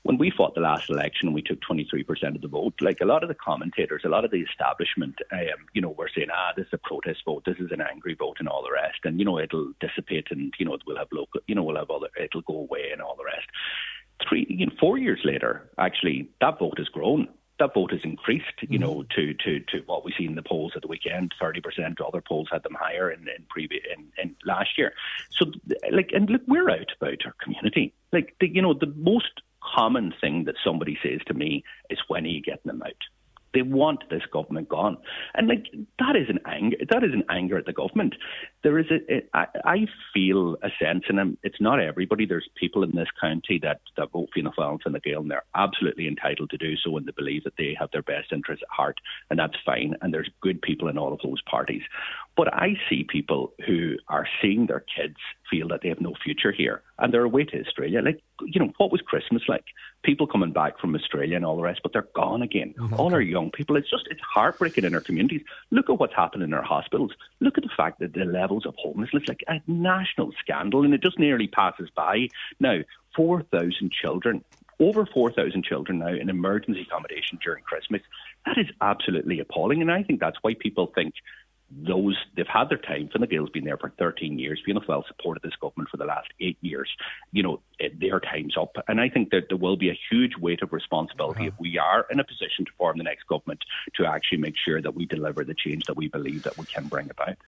Deputy Doherty told today’s Nine til Noon Show that he believes the appetite for change is there: